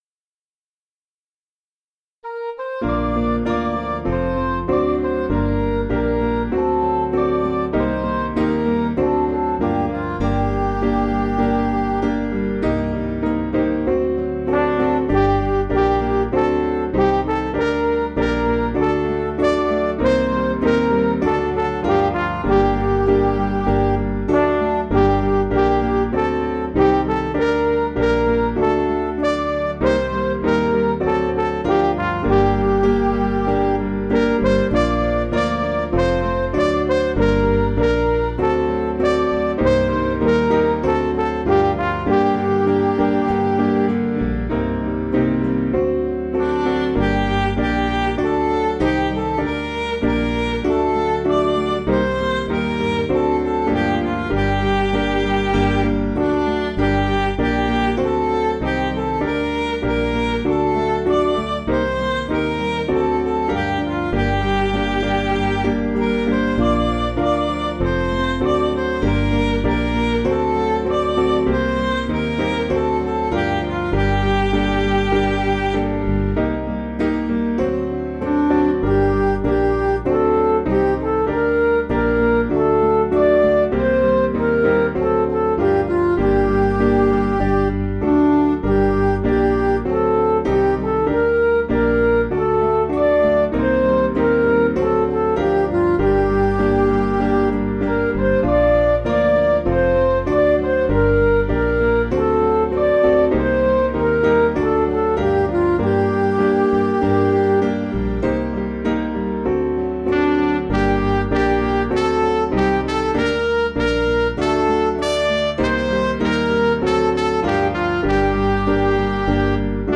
Piano & Instrumental
(CM)   4/Gm